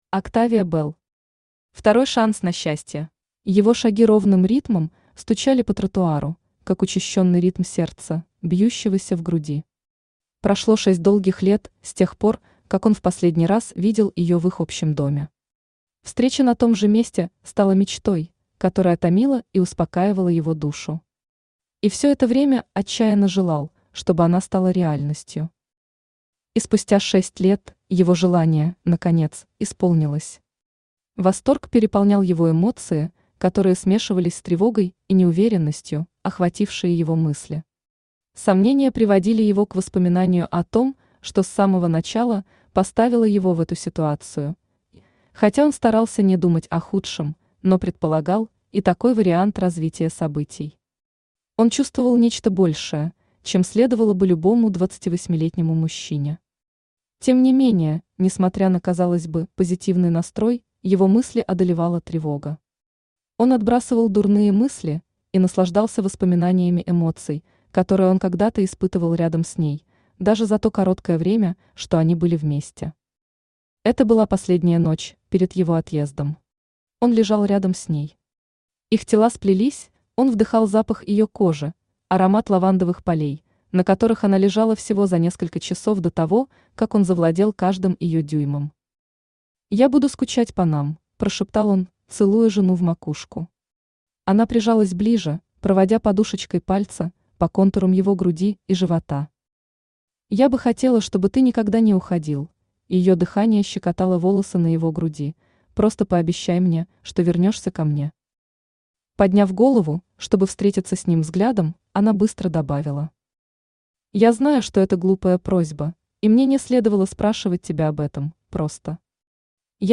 Аудиокнига Второй шанс на счастье | Библиотека аудиокниг
Aудиокнига Второй шанс на счастье Автор Октавия Белл Читает аудиокнигу Авточтец ЛитРес.